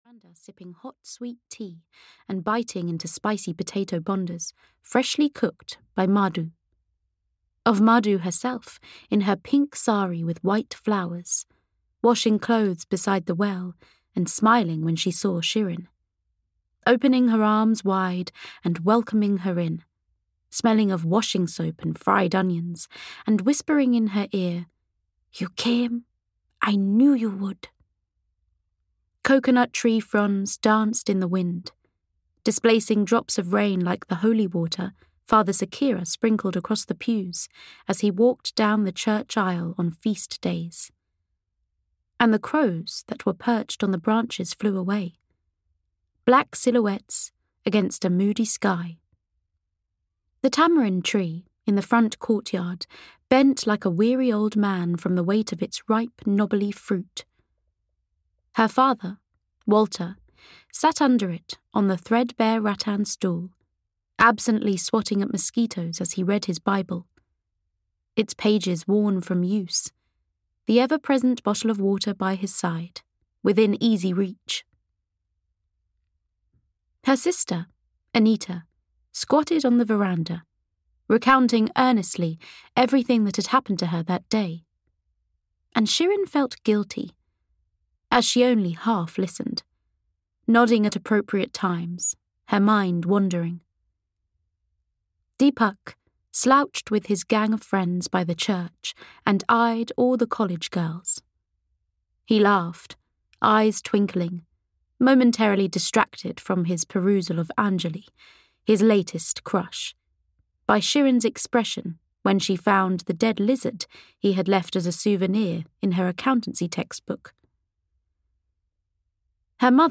Monsoon Memories (EN) audiokniha
Ukázka z knihy